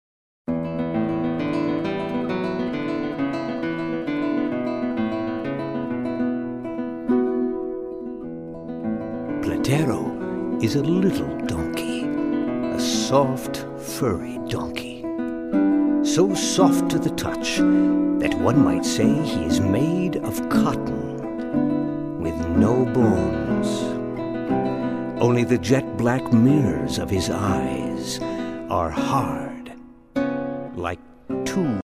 Guitar
Narrator